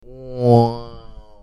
Wow Sound Button - Free Download & Play